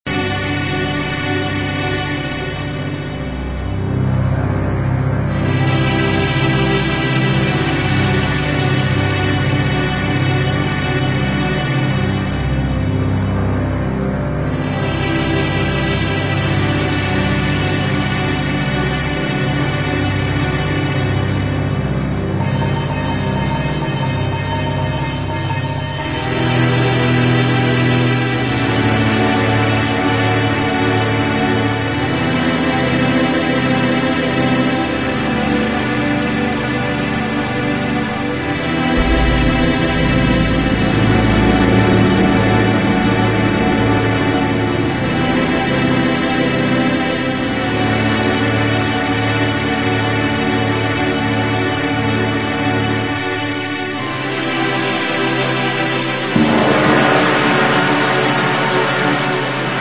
um disco en�rgico e ao mesmo tempo mel�dico
rock progressivo brasileiro
teclados.
Bateria e Percuss�o
Guitarras
Viol�o